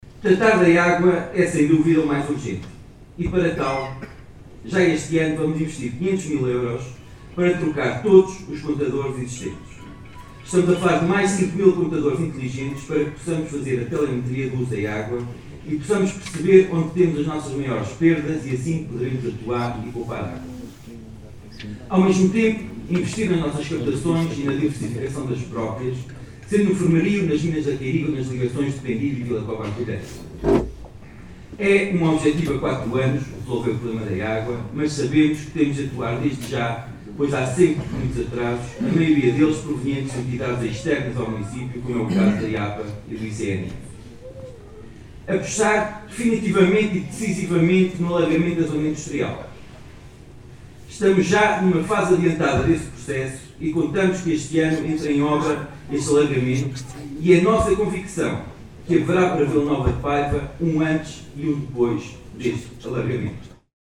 P.Marques-Discurso-2-marco-2026-Investimentos.mp3